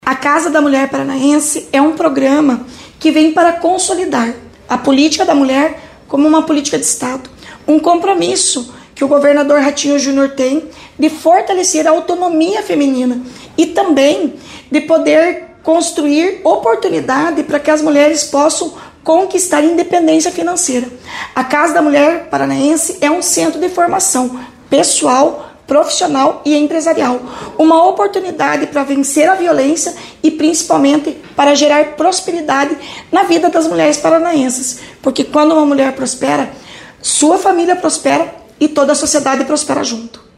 Sonora da secretária Estadual da Mulher, Igualdade Racial e Pessoa Idosa, Leandre dal Ponte, sobre o decreto que regulamenta o programa Casa da Mulher Paranaense